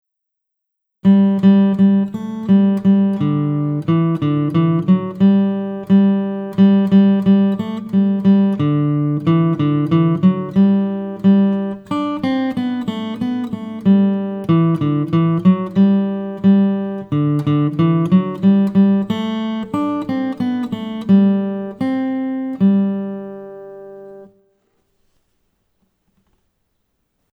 DADGAD Tuning, Guitar Solo
DIGITAL SHEET MUSIC - FINGERPICKING SOLO